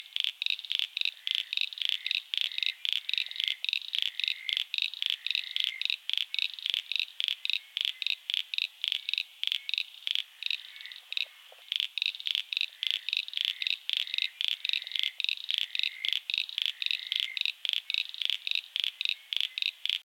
Common Eastern Froglet recorded by Australian Museum